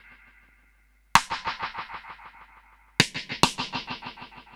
Index of /musicradar/dub-drums-samples/105bpm
Db_DrumsA_SnrEcho_105_02.wav